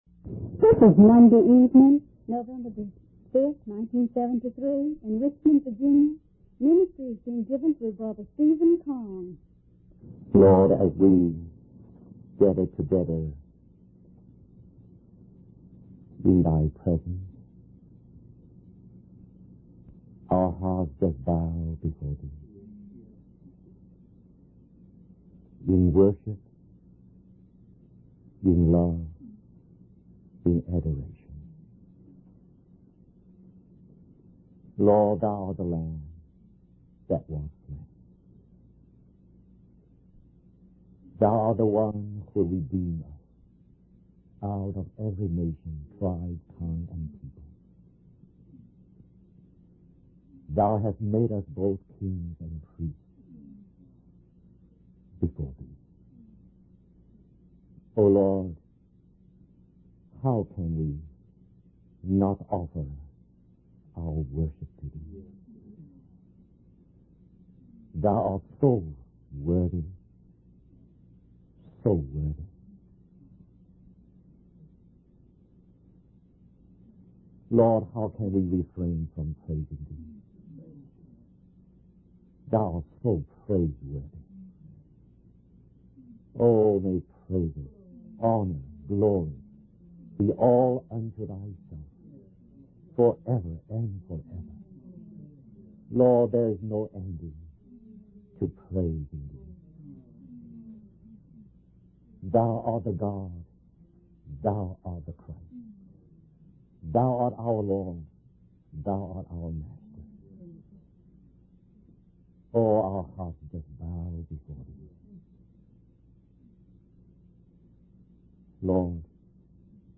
In this sermon, the speaker emphasizes the importance of being diligent in our daily lives as believers. He encourages the congregation to come prepared and ready to contribute when they gather together.